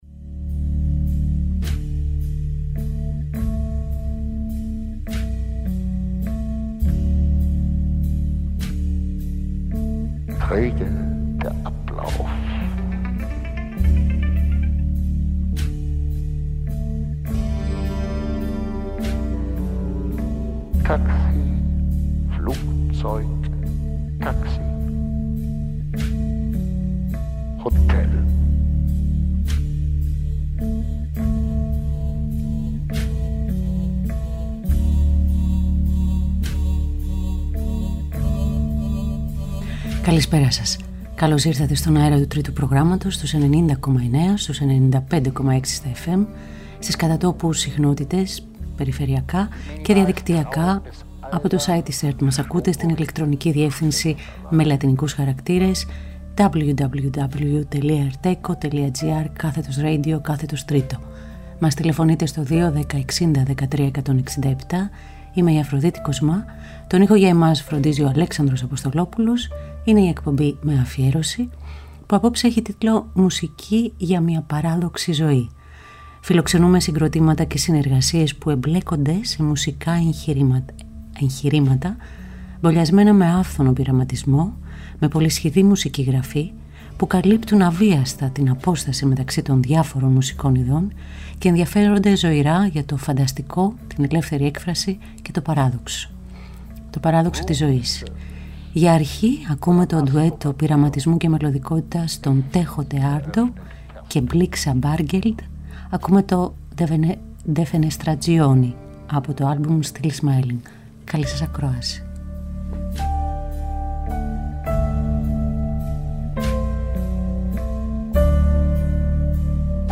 Ζωντανά από το στούντιο του Τρίτου Προγράμματος.